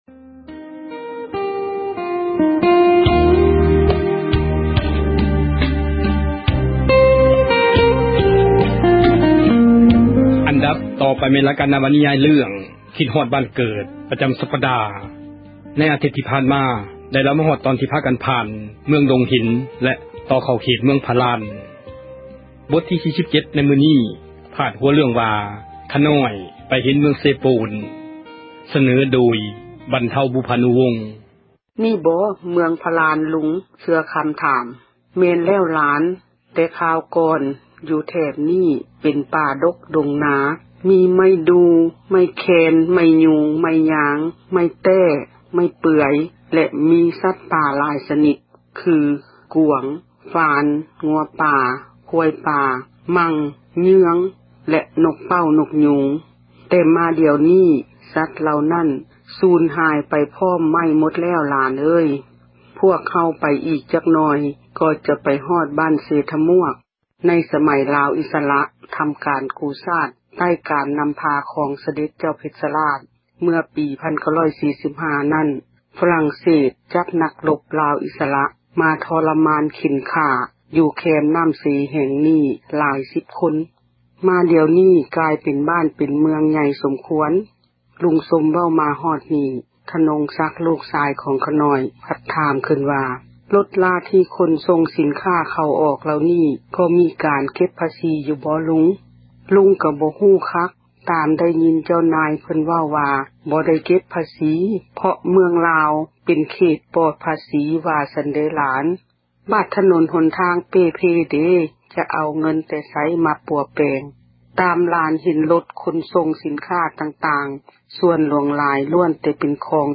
ນິຍາຍ ເຣື້ອງ ຄິດຮອດບ້ານເກີດ ປະຈຳສັປດາ ບົດທີ 46.